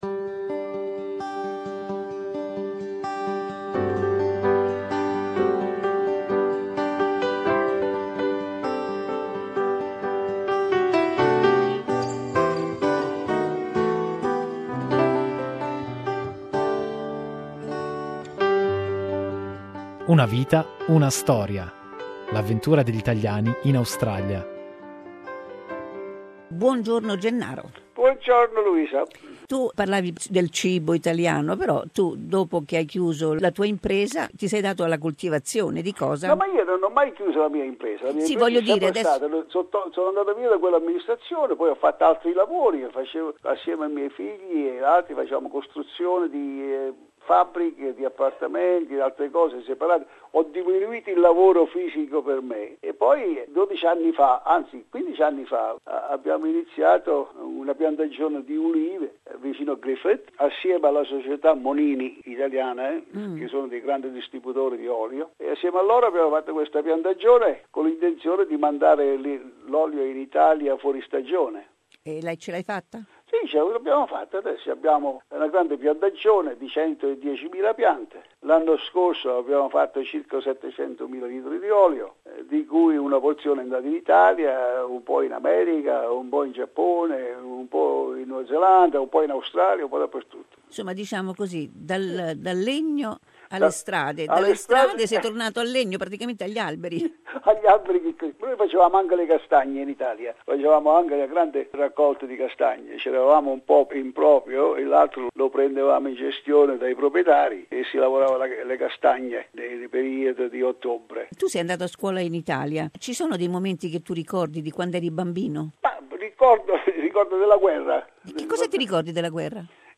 Part 2 of our interview with successful entrepreneur